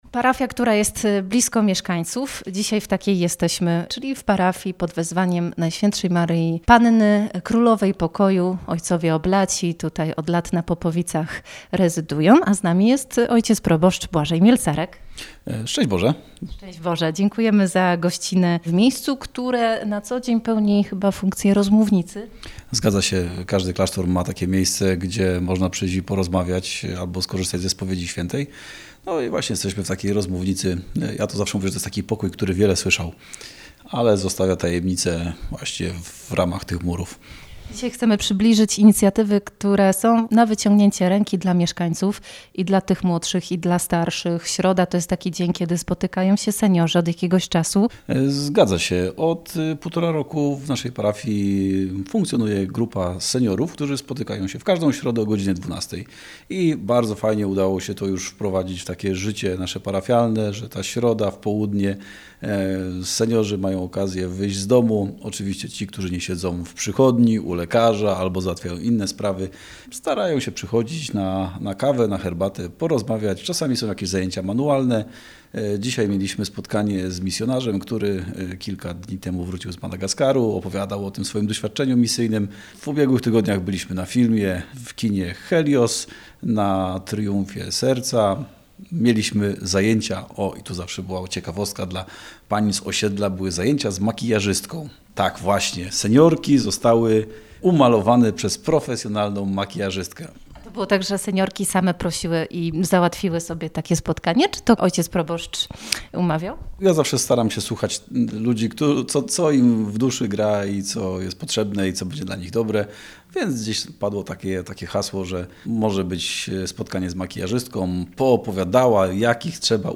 Gościem programu jest